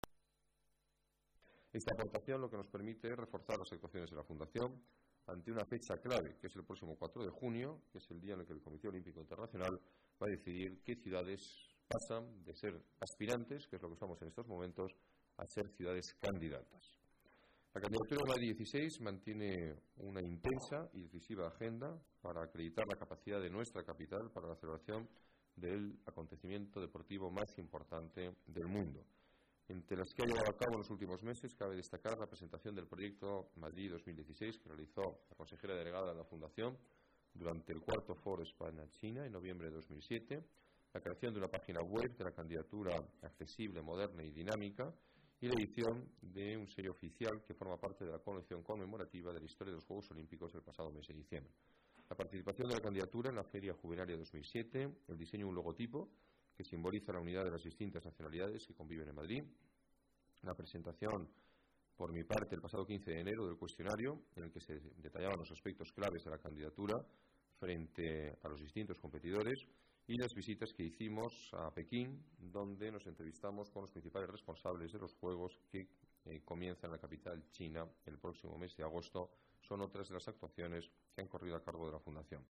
Nueva ventana:Declaraciones del alcalde Alberto Ruiz-Gallardón: nuevo impulso a la candidatura olímpica